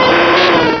sovereignx/sound/direct_sound_samples/cries/arcanine.aif at master
arcanine.aif